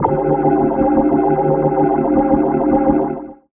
Organ_LP.wav